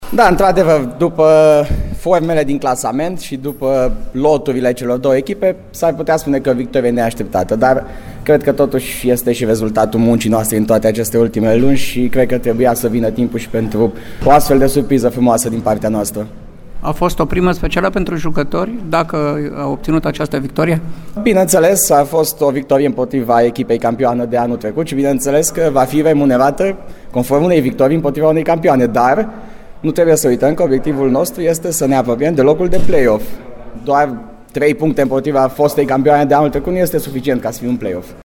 Reacțiile de după meci au fost ”culese” de la fața locului